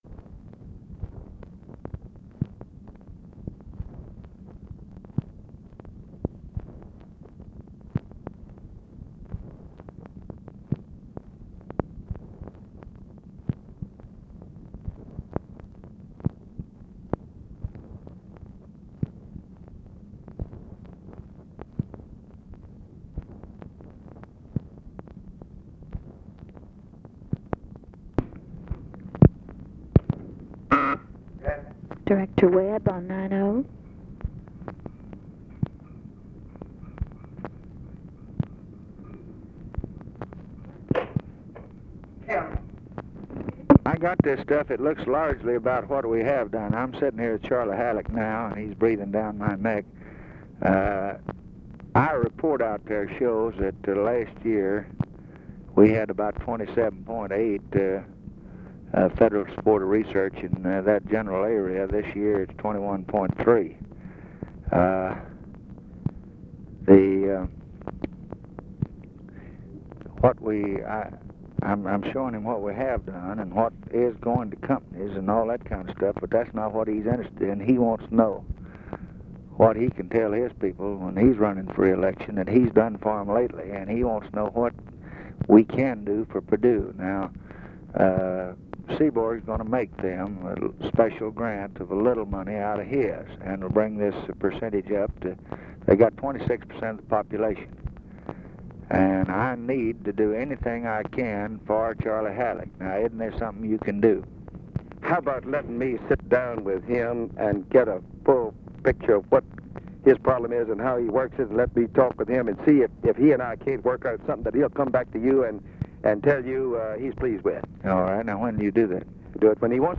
Conversation with JAMES WEBB, January 18, 1964
Secret White House Tapes